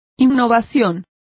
Complete with pronunciation of the translation of novelties.